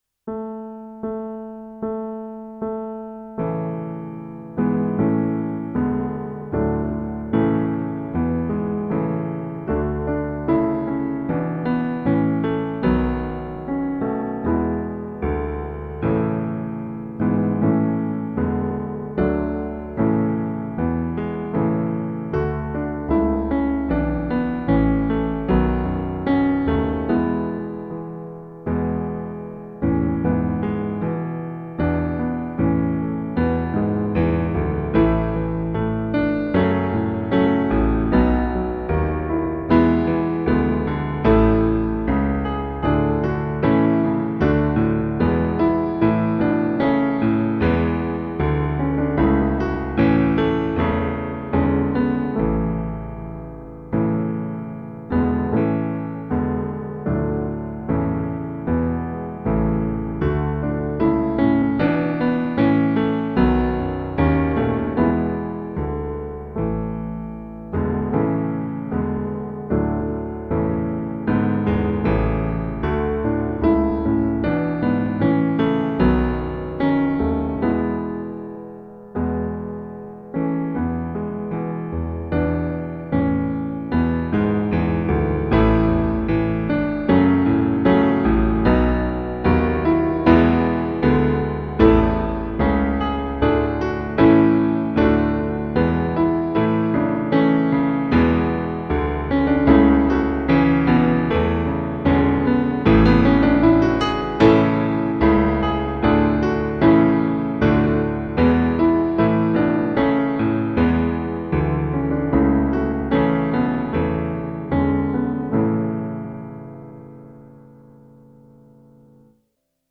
Übungsaufnahmen - Kinderhymne
Kinderhymne (Playback)
Kinderhymne__4_Playback.mp3